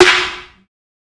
kits/OZ/Snares/SN_Trust.wav at main